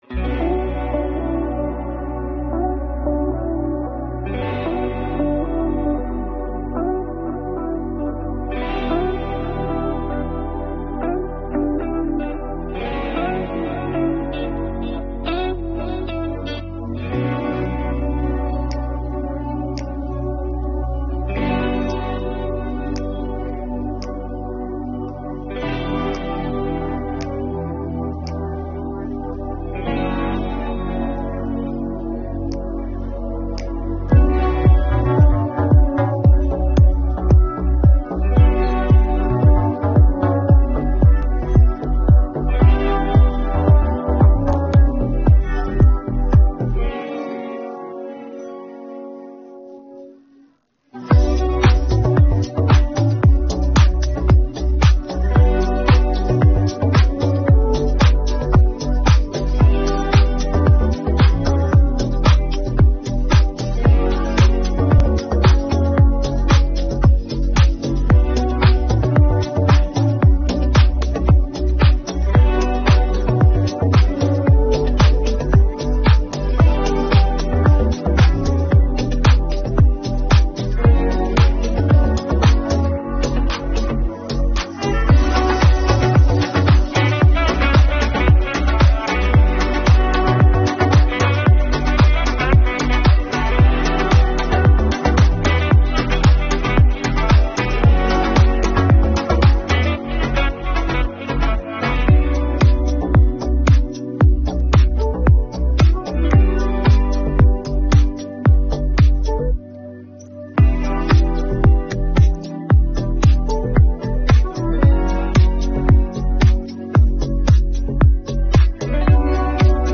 мінус